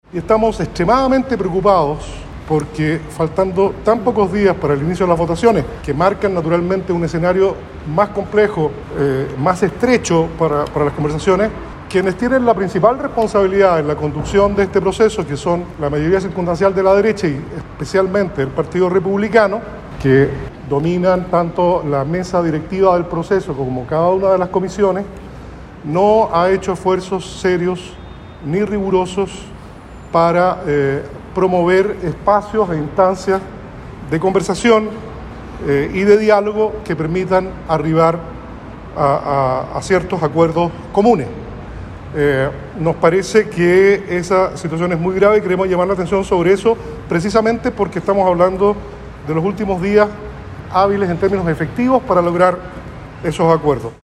La vocería estuvo a cargo del consejero Yerko Ljubetic (CS), quien manifestó su preocupación por la cada vez más lejana posibilidad de constituir «un pacto político que dé lugar a una propuesta constitucional para la ciudadanía», esfuerzo que, aseguró, ha sido liderado por su bloque a través de la proposición de distintas instancias que, a pesar de su antelación, no fueron consideradas.